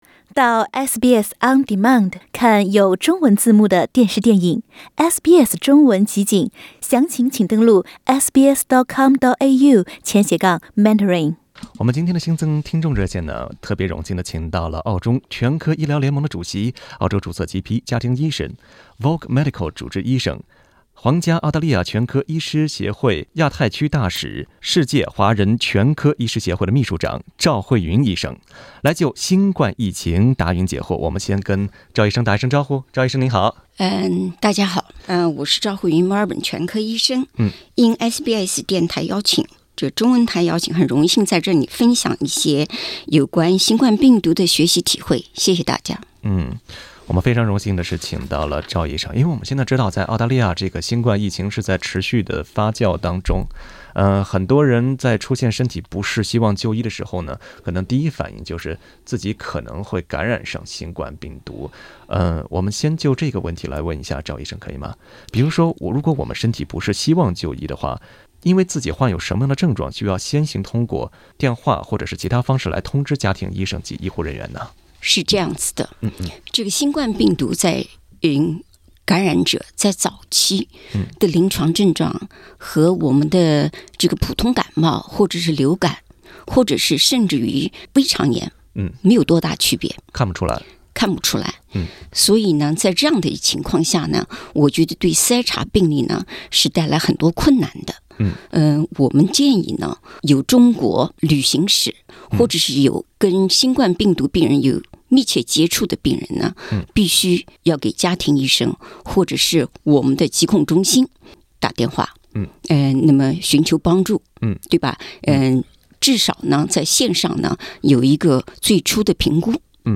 听众答疑 Australian evacuees inside the Christmas Island detention centre, which is being used as a quarantine area for the novel coronavirus.